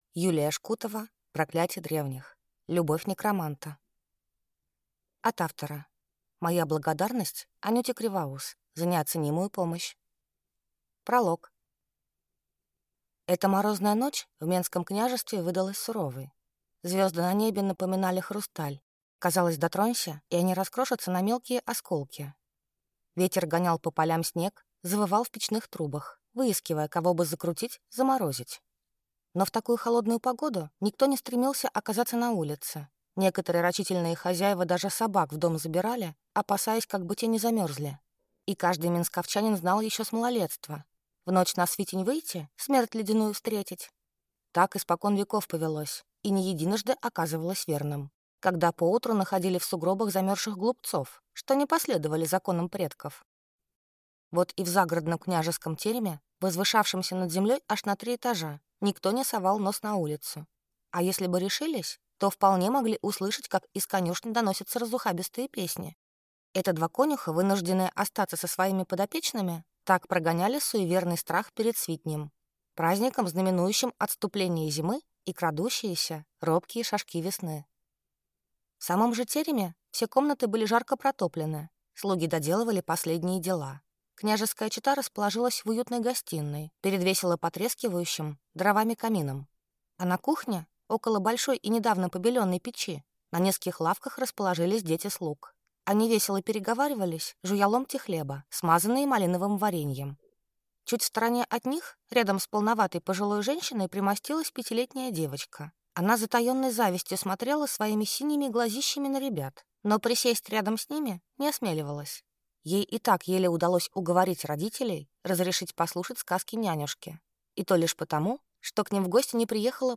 Аудиокнига Проклятие древних. Любовь некроманта | Библиотека аудиокниг